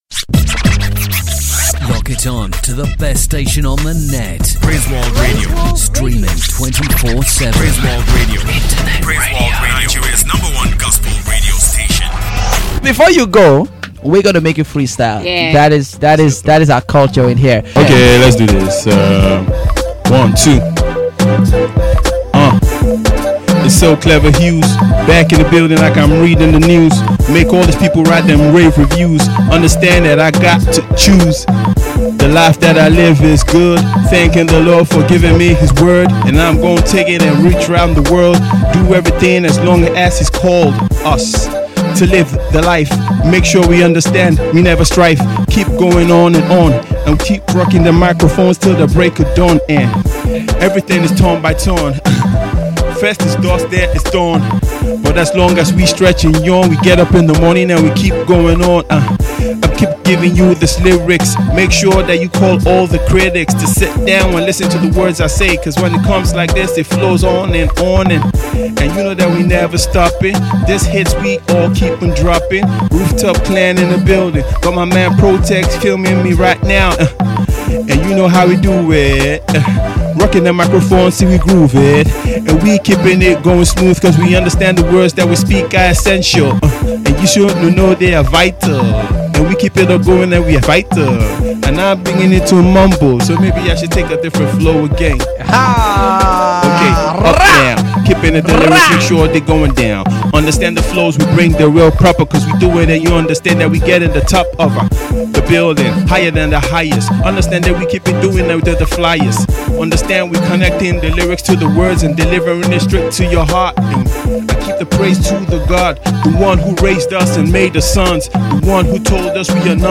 he made the rapper jump on the beat in a fun freestyle session.